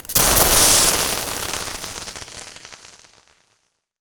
elec_lightning_magic_spell_07.wav